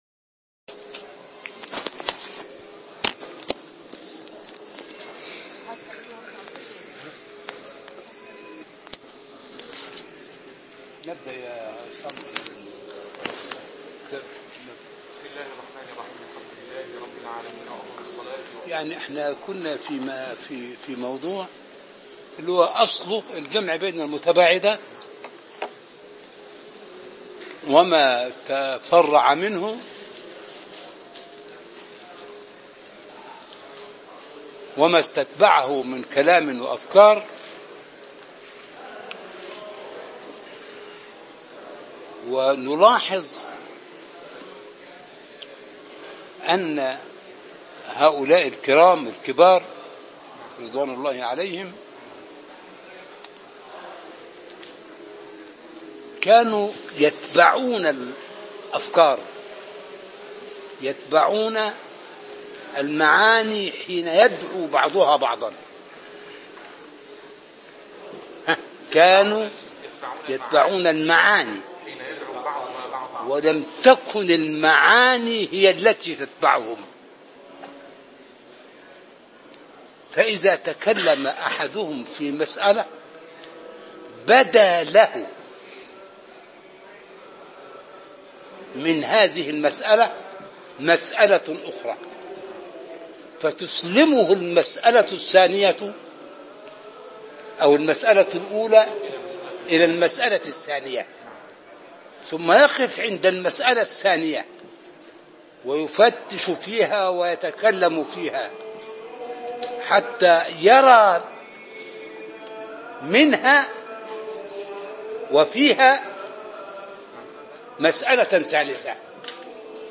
عنوان المادة الدرس الخمسون (شرح كتاب أسرار البلاغة) تاريخ التحميل الأربعاء 21 سبتمبر 2016 مـ حجم المادة 20.29 ميجا بايت عدد الزيارات 739 زيارة عدد مرات الحفظ 255 مرة إستماع المادة حفظ المادة اضف تعليقك أرسل لصديق